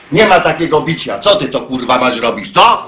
Worms speechbanks
TRAITOR.wav